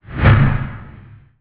Woosh_20.wav